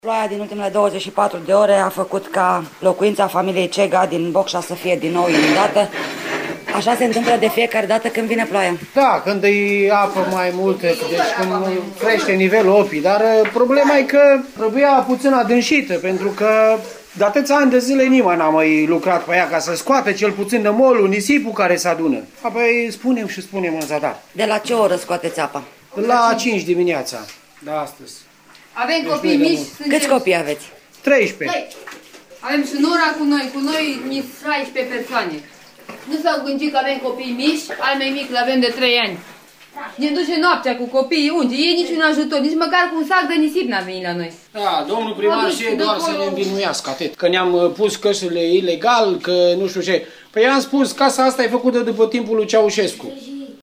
Una dintre familile afectate: